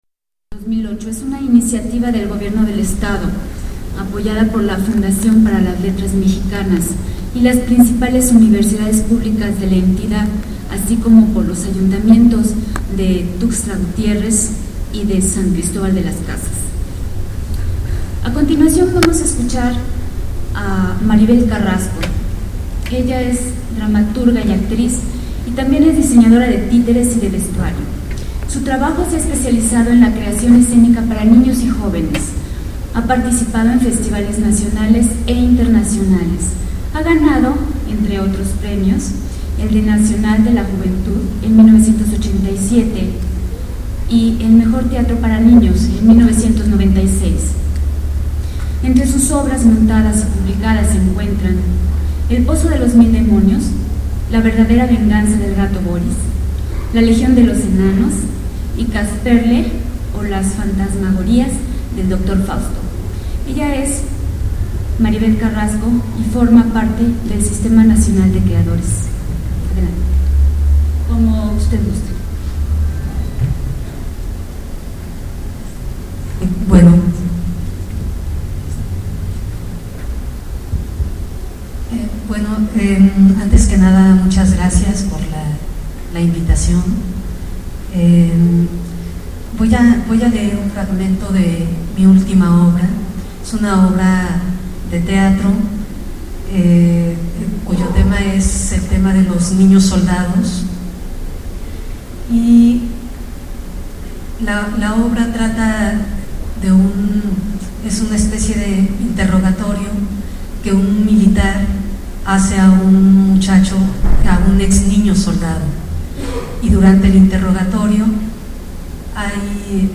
Lugar: Teatro Daniel Zebadúa de San Cristóbal de Las Casas, Chiapas.
Equipo: iPod 2Gb con iTalk Fecha: 2008-11-10 12:06:00 Regresar al índice principal | Acerca de Archivosonoro